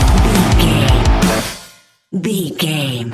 Ionian/Major
guitars
heavy metal
instrumentals